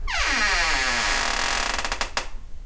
sounds_door_creak.ogg